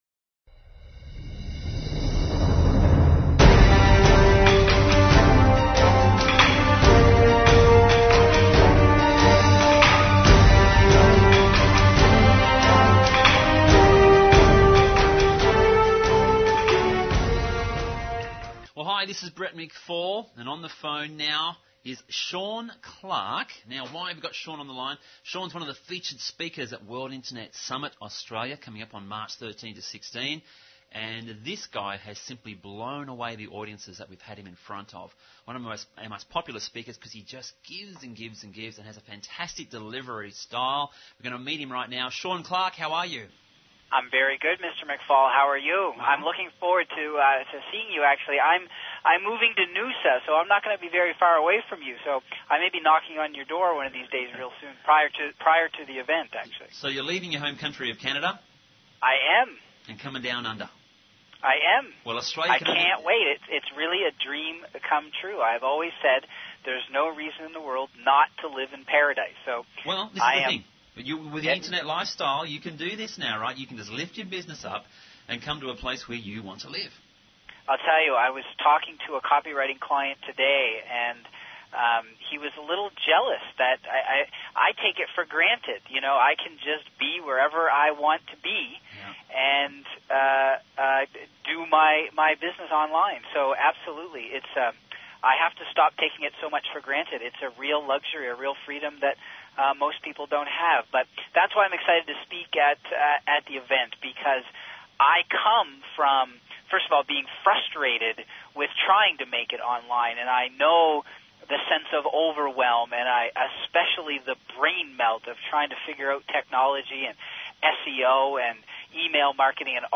Click the 'play' button above to hear a revealing, short interview